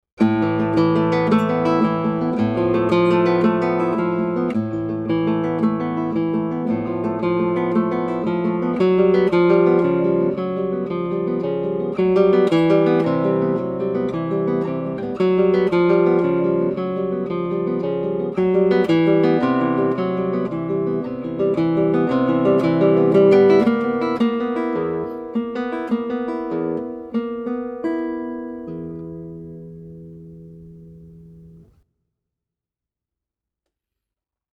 It lacks melody but, instead, expresses agitation through the texture and color of rapidly repeated arpeggios.
Use the P-i-m arpeggio pattern to play the triplets in Dark Waves.
guitar